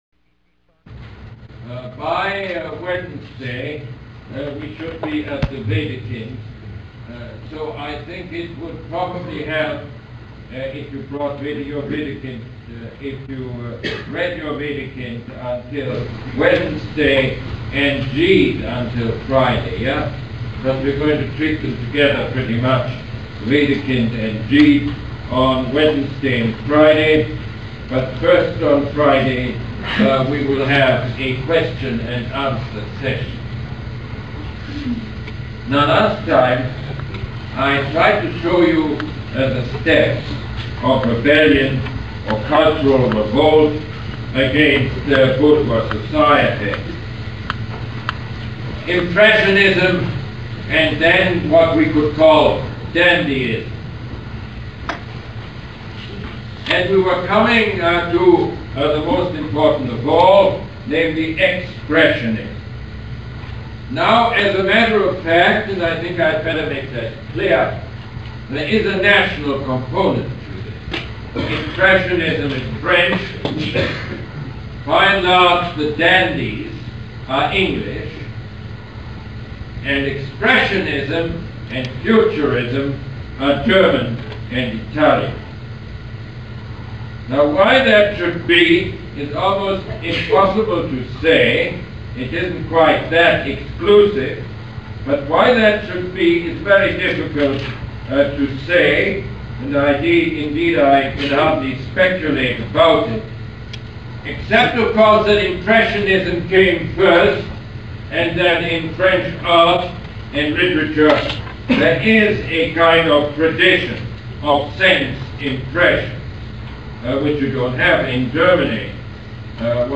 Lecture #6 - September 24, 1979